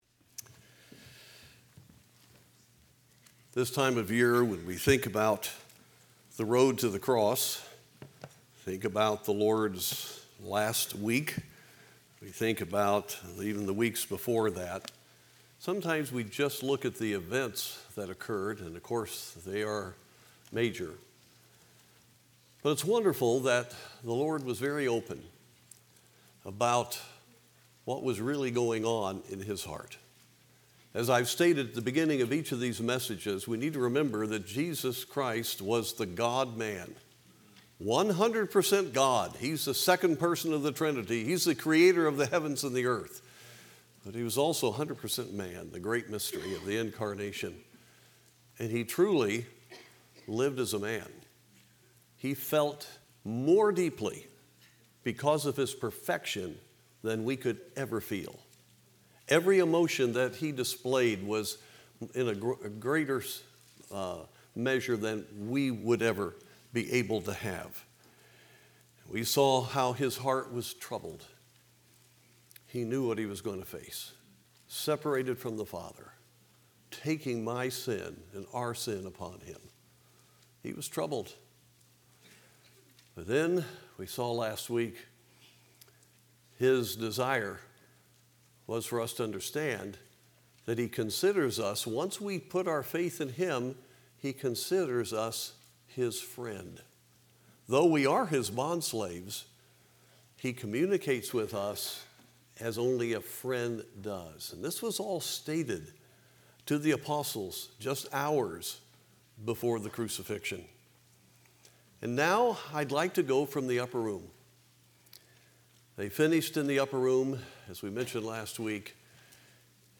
Recent Sermon